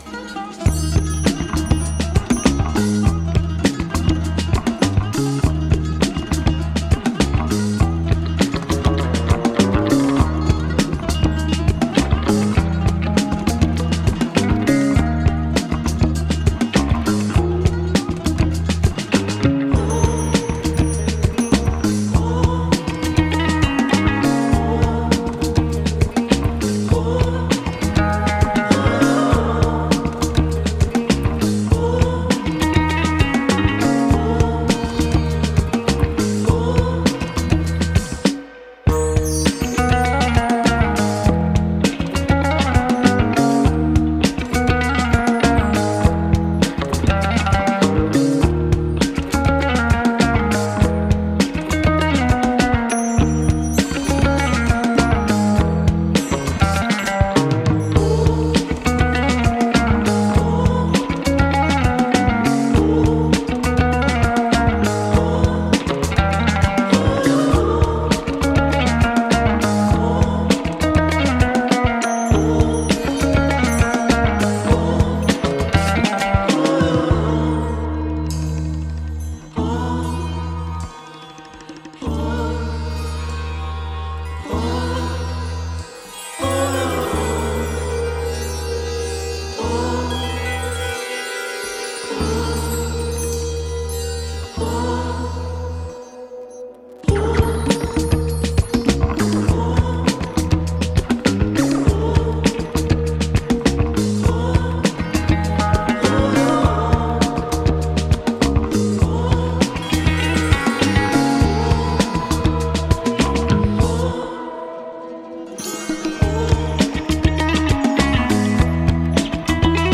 Psychedelic Rock, Funk